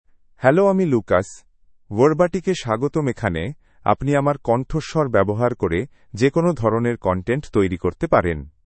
Lucas — Male Bengali AI voice
Lucas is a male AI voice for Bengali (India).
Voice sample
Listen to Lucas's male Bengali voice.
Male
Lucas delivers clear pronunciation with authentic India Bengali intonation, making your content sound professionally produced.